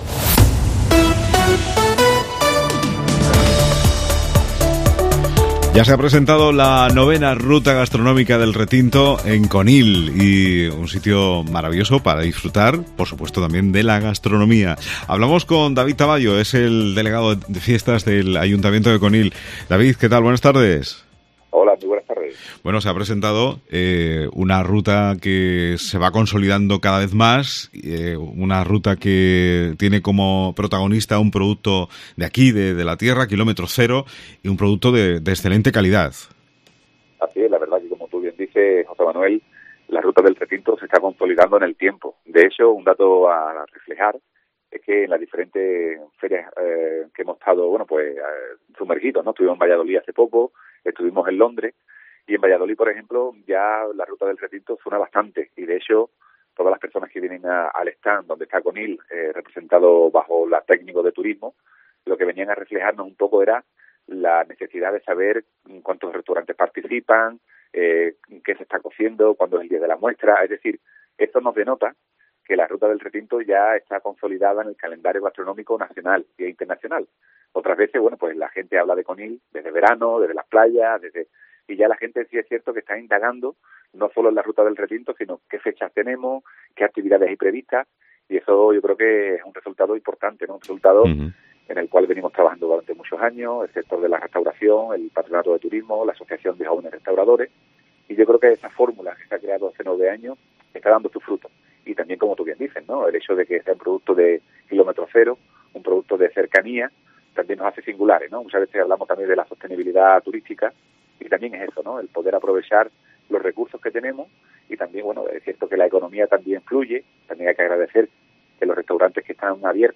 Con el Patronato de Turismo de Conil hablamos en Mediodía COPE Provincia de Cádiz, de la presentación de la novena edición de la Ruta Gastronómica del Retinto. El concejal de Turismo David Tamayo ha ofrecido detalles de La Ruta del Retinto, que se celebrará del 2 al 11 de diciembre, es una de las tres rutas que organiza anualmente el Ayuntamiento de Conil, a través del Patronato Municipal de Turismo, junto con la Asociación Jóvenes Restauradores.